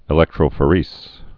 (ĭ-lĕktrō-fə-rĕs)